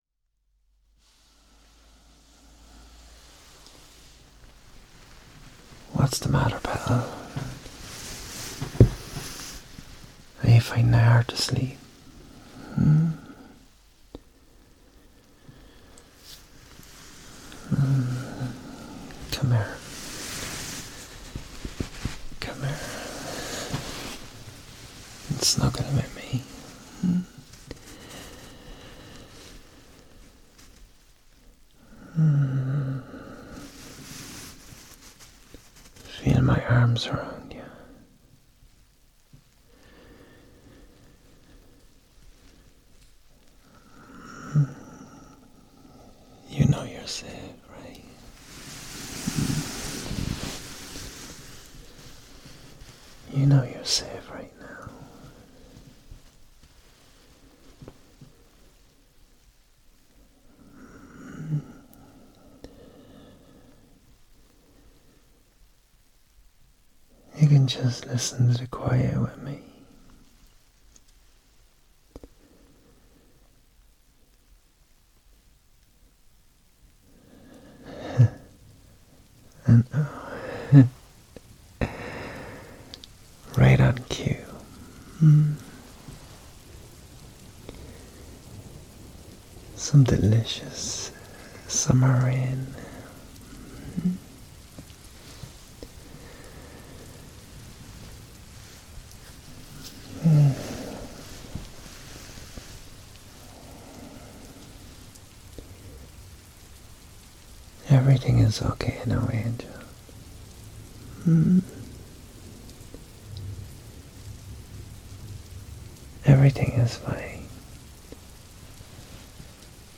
A bit of a normal sleep audio with some gently spoken Rumi for about 25 minutes that fades into rainfall.
☘ ( Irish Accent ) Socials